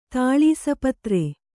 ♪ tāḷīsa patre